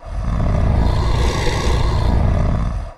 wolf_idle_1.ogg